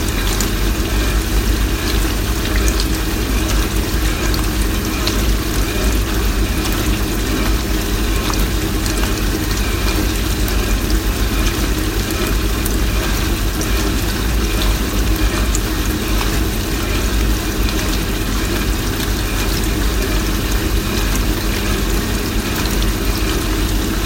洗衣机
标签： 汩汩 飞溅
声道立体声